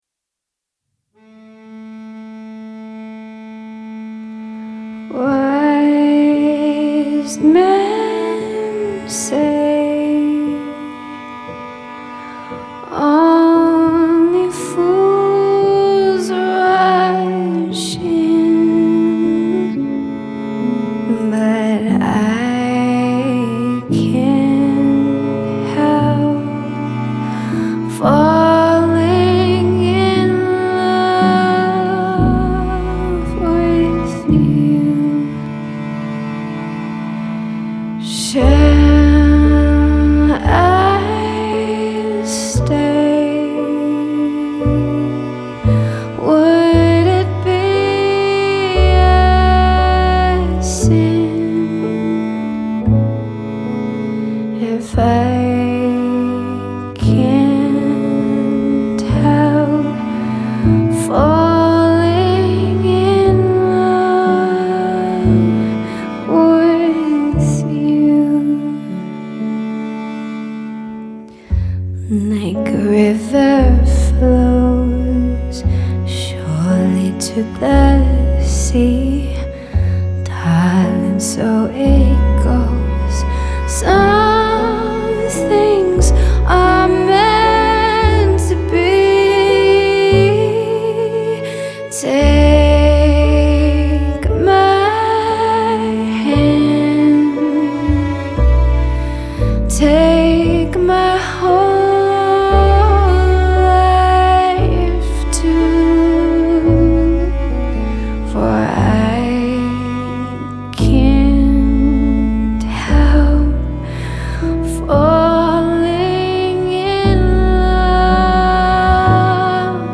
Vocals | Guitar | Double | Bass
Double bass, acoustic guitar and vocals